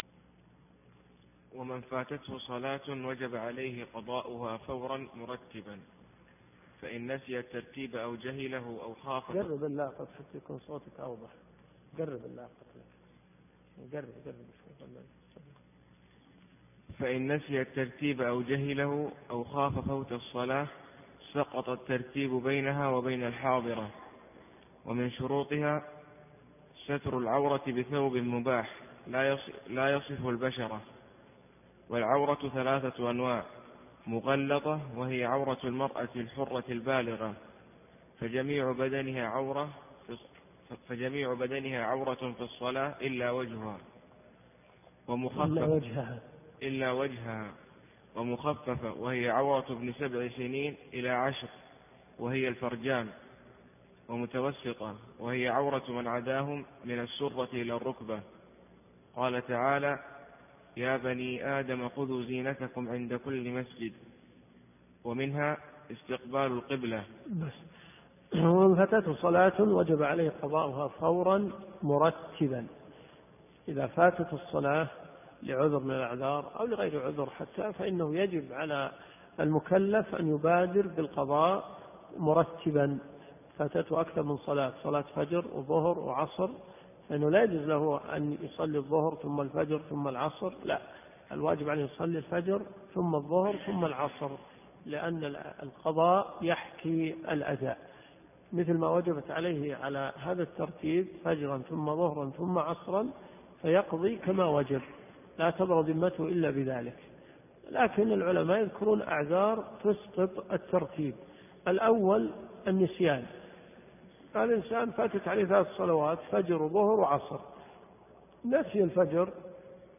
الدروس الشرعية